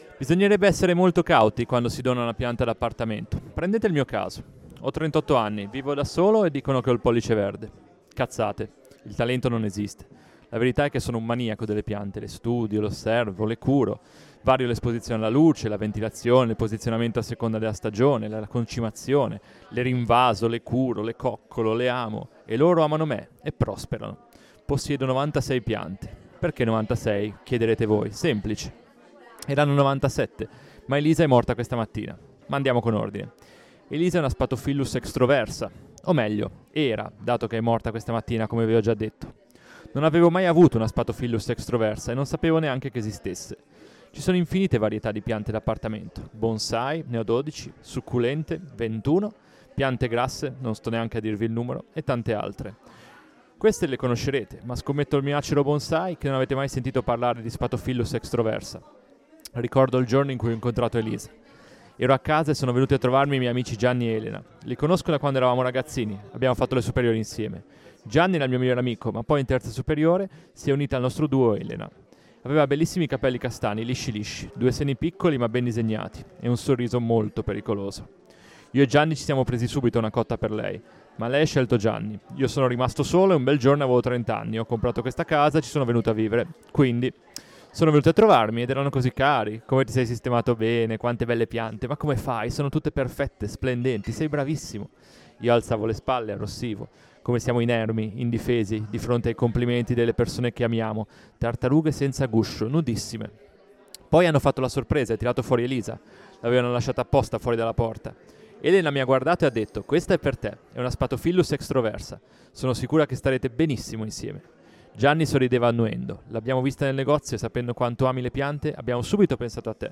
Un lunedì al mese, presso il ristorante Kowalski di via dei Giustiniani 3r, possibilità di leggere di fronte a una giusta platea i propri scritti autografi.
UniGe Radio pubblica il seguente podcast, registrato dal vivo, integralmente e senza modifiche, nel pieno rispetto dell'integrità artistica e della libera manifestazione del pensiero degli autori.